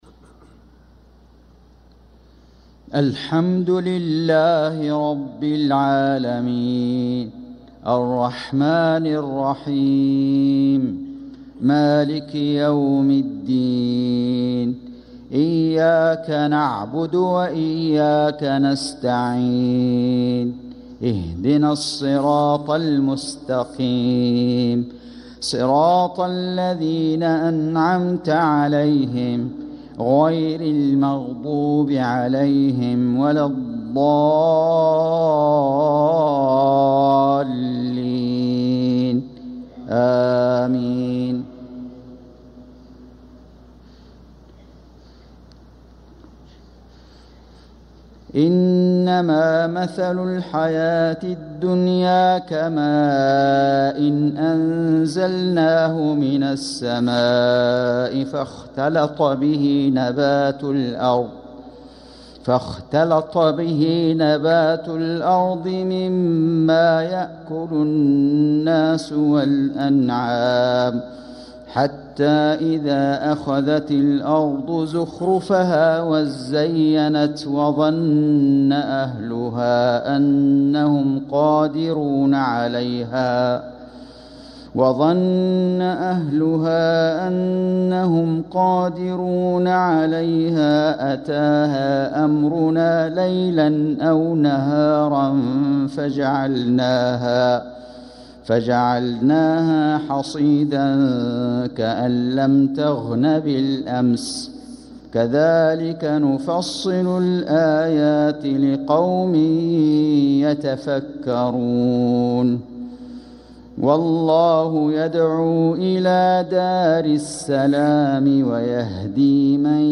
صلاة المغرب للقارئ فيصل غزاوي 11 صفر 1446 هـ